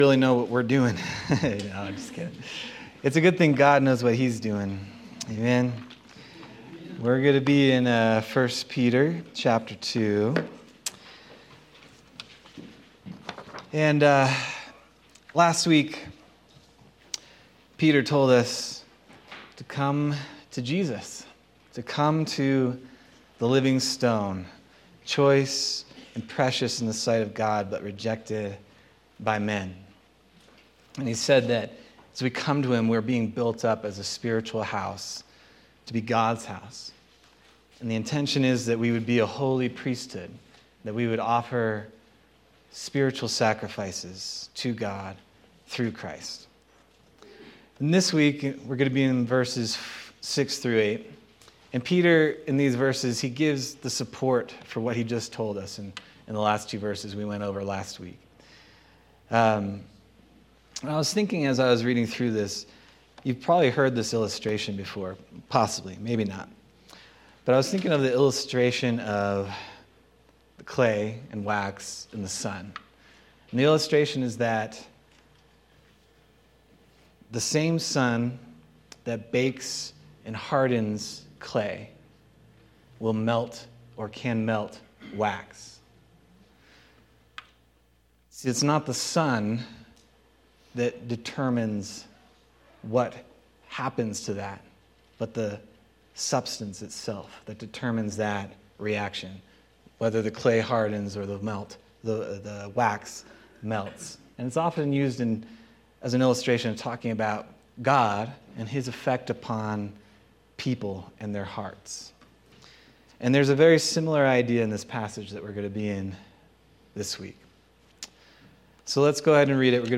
February 2nd, 2025 Sermon